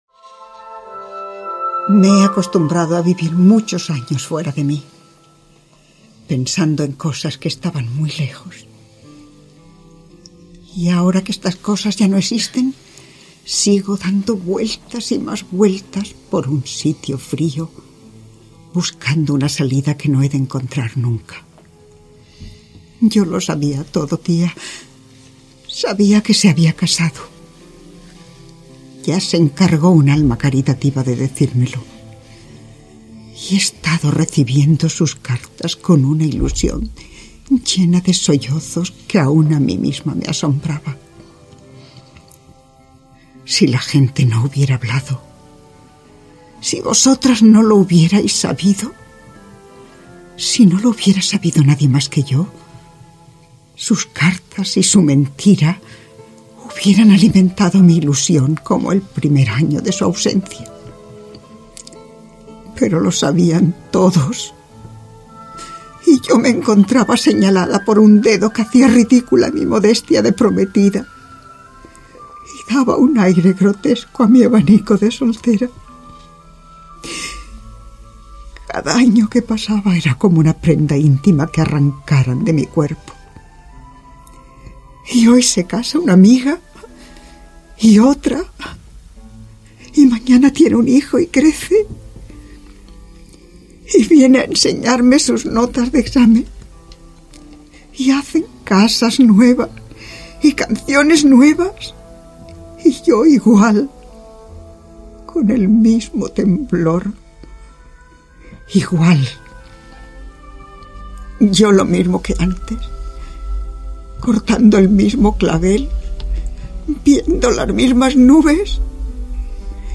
Radioteatre: Doña Rosita - Ràdio Terrassa, 2004